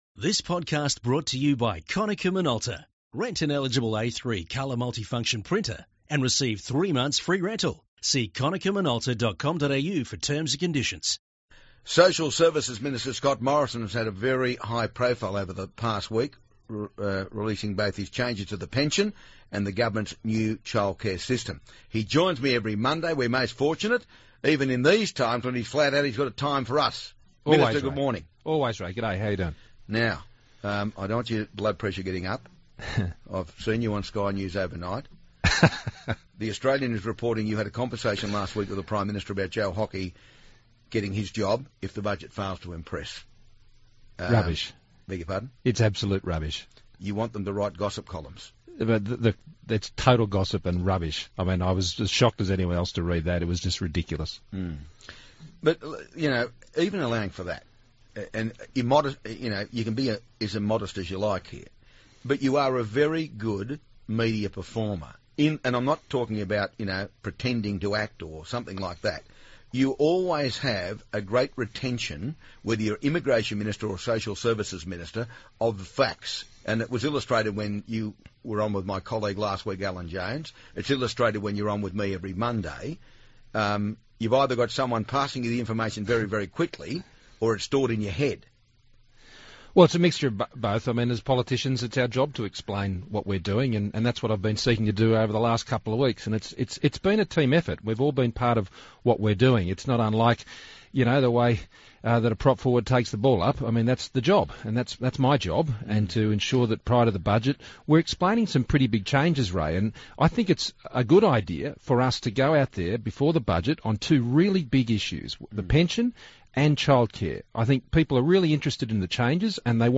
Social Services Minister Scott Morrison talks to Ray about rumours he wants Treasurer Joe Hockey’s job, changes to childcare and answers listeners’ questions about changes to the aged pension